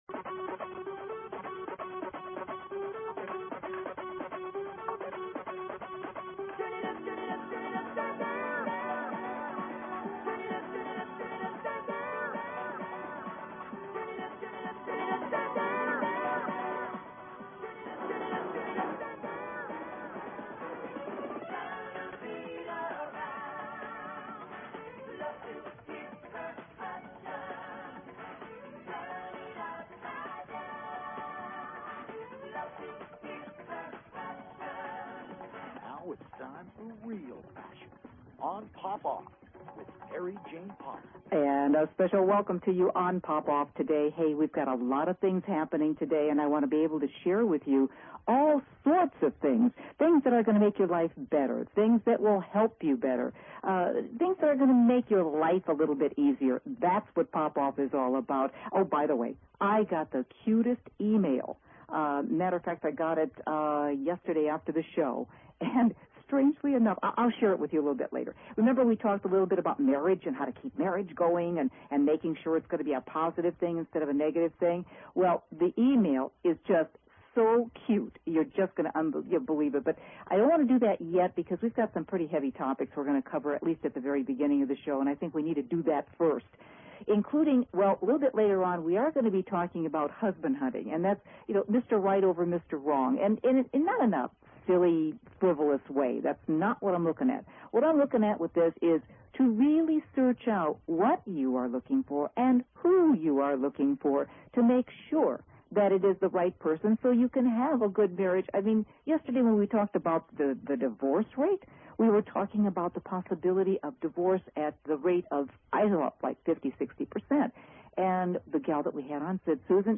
Talk Show Episode, Audio Podcast, Poppoff and Courtesy of BBS Radio on , show guests , about , categorized as
A fast-paced two hour Magazine-style Show dedicated to keeping you on the cutting edge of today's hot button issues.